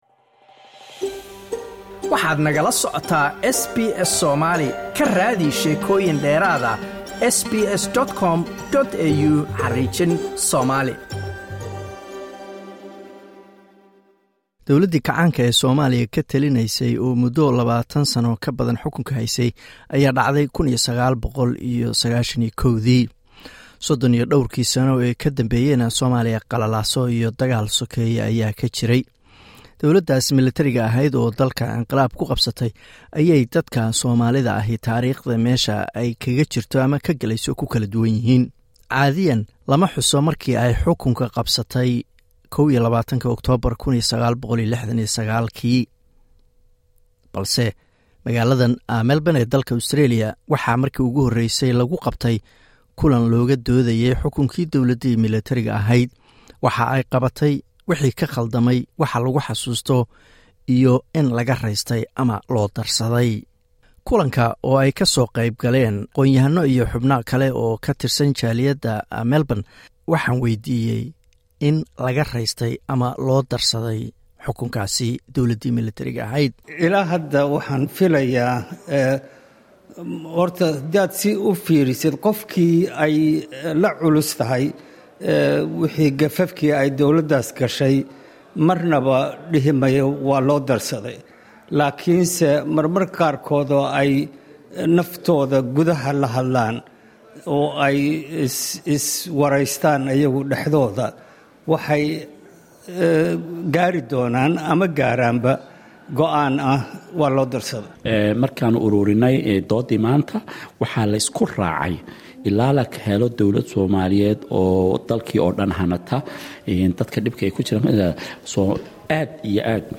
Waxaan kulan ka dhacay Melbourne waydiinay aqoon yahano in dawladii Maxamed Siyad barre loo darsaday ama laga raystay.